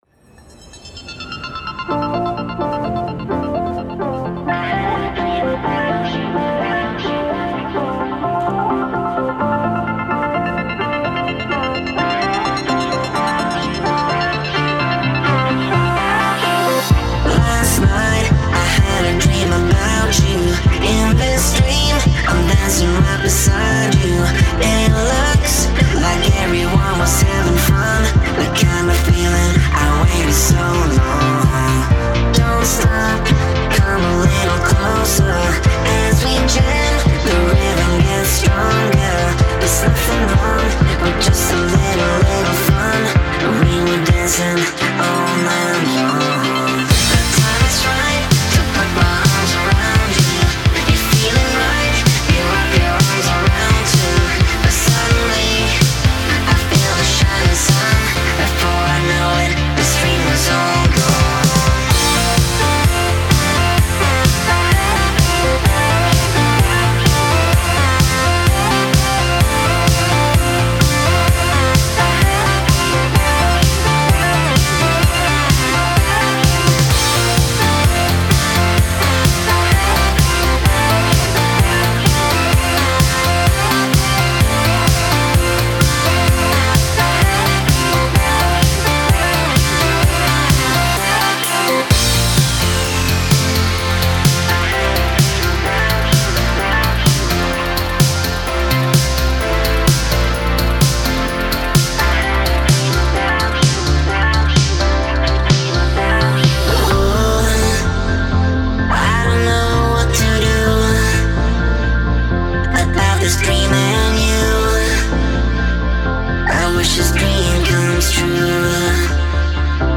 cover
synthwave